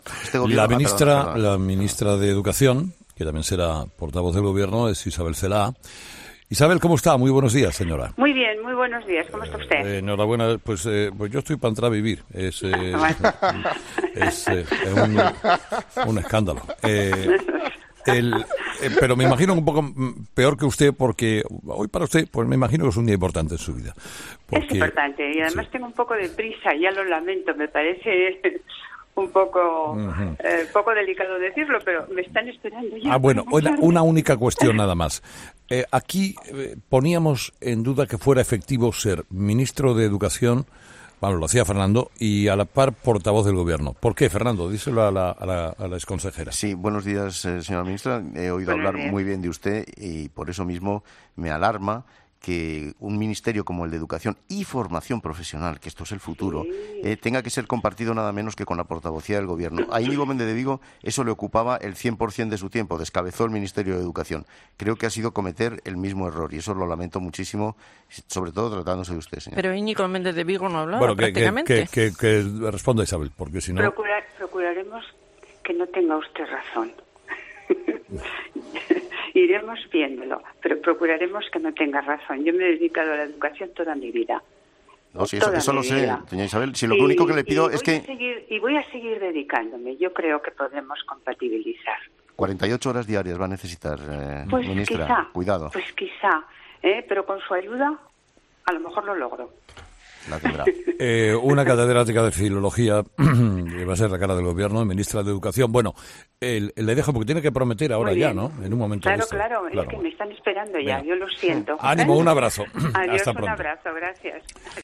La recién designada ministra de Educación y Formación Profesional, Isabel Celaá, que será también ministra portavoz del Ejecutivo, se ha mostrado confiada en 'Herrera en COPE' de “poder compatibilizar” ambos cargos en el nuevo gobierno de Sánchez aunque esto quizás le lleve “48 horas diarias”. “Me he dedicado a la educación toda mi vida y voy a seguir dedicándome”, ha señalado brevemente desde el Palacio de la Zarzuela minutos antes de jurar o prometer su cargo ante el Rey.